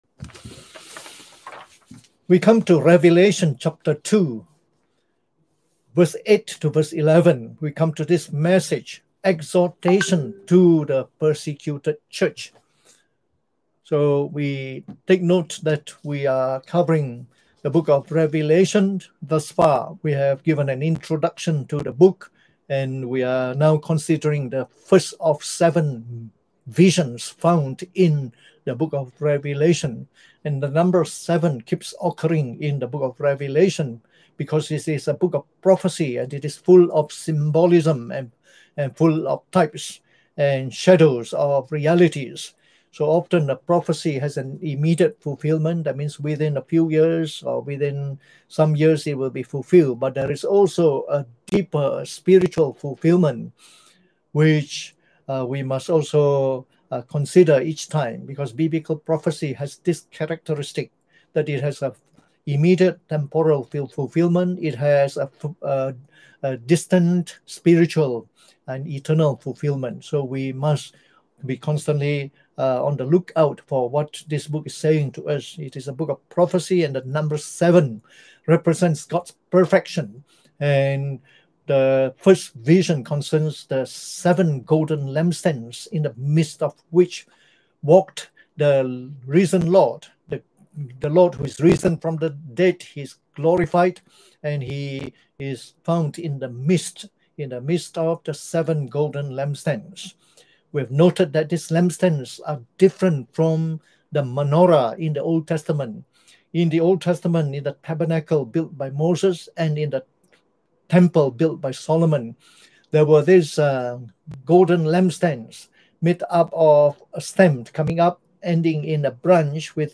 Preached on the morning of the 24th of January 2021